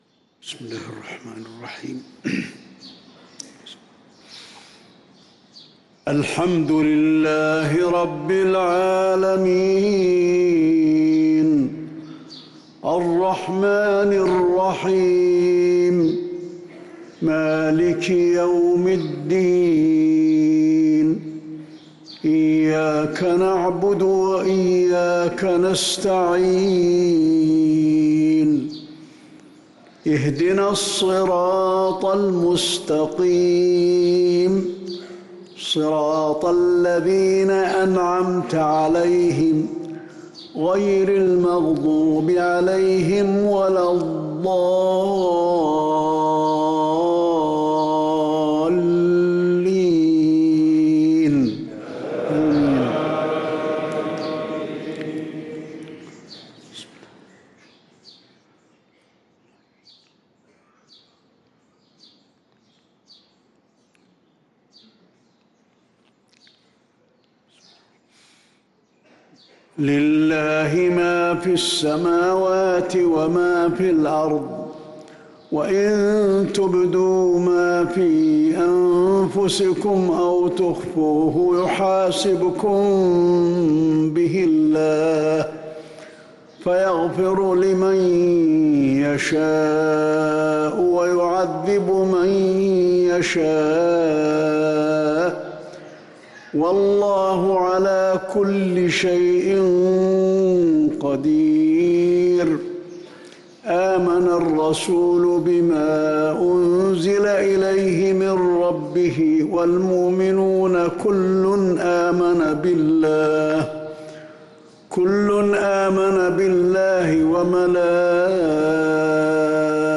صلاة المغرب للقارئ علي الحذيفي 28 رجب 1445 هـ
تِلَاوَات الْحَرَمَيْن .